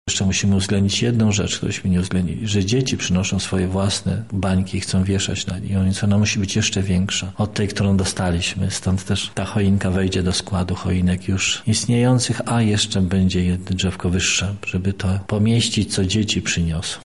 -mówi ksiądz